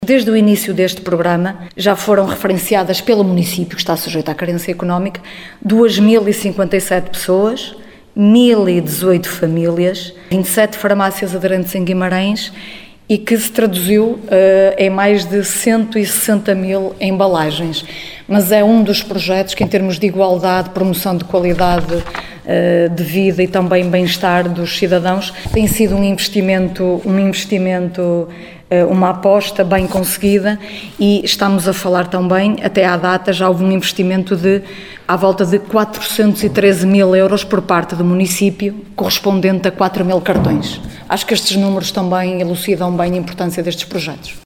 Paula Oliveira, vereadora da Ação Social no Município de Guimarães.